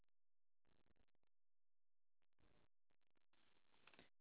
kyo 0141 (Monaural AU Sound Data)